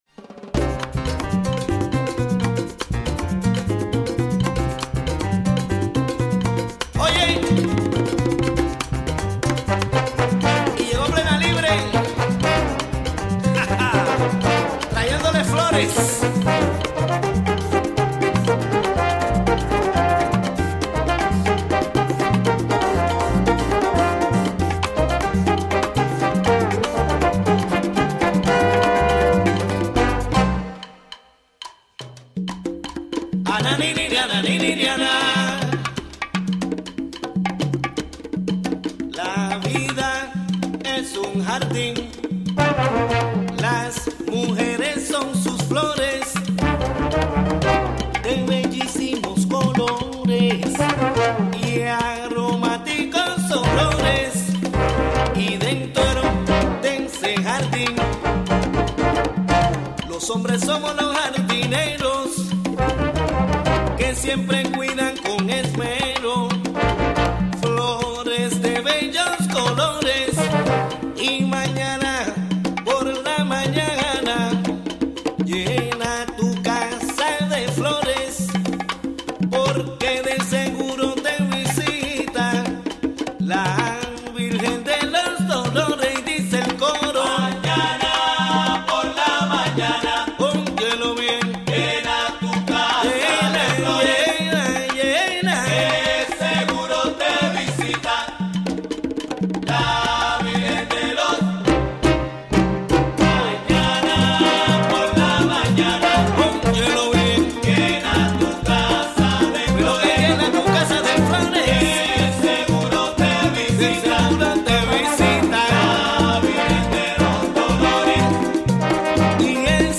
Discussion of the recent findings by the New York state attorney general that only 21 percent of herbal supplements sold by major retailers contain the ingredients listed in the label. Interview